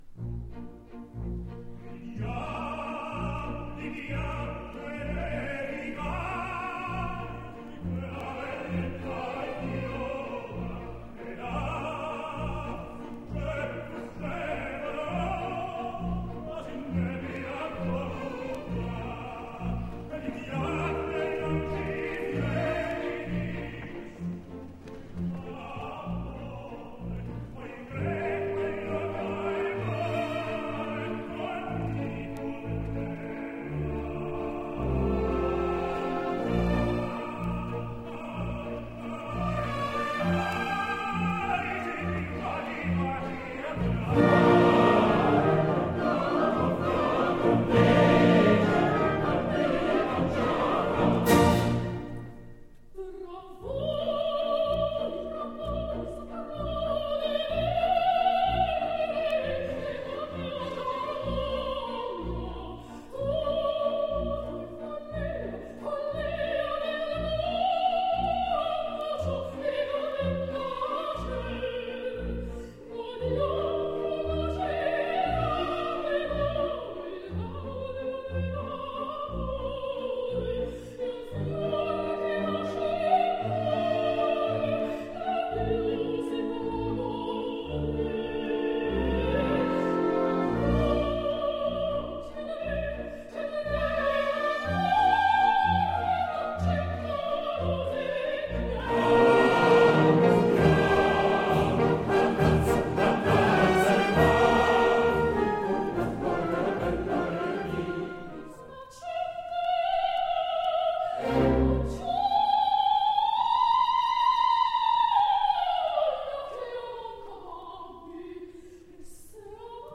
a voice of agility, accuracy, warmth, brilliance and power